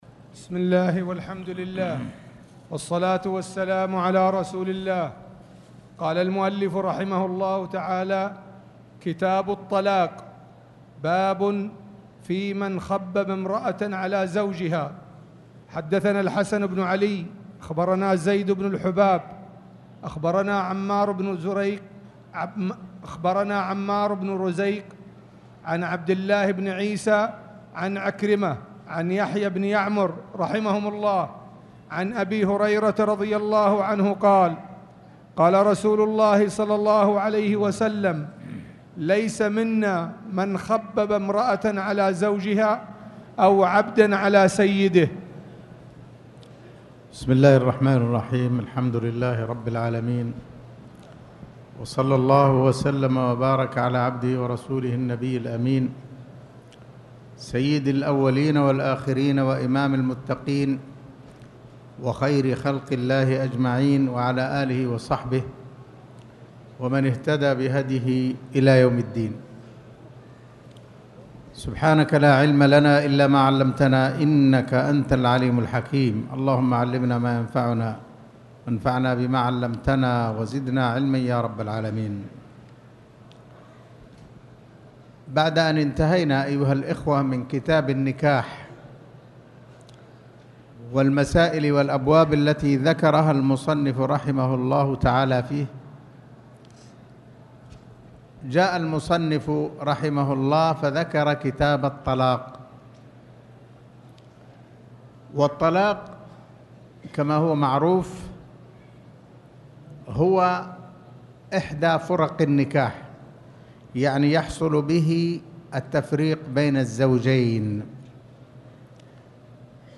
تاريخ النشر ١٨ ربيع الأول ١٤٣٨ هـ المكان: المسجد الحرام الشيخ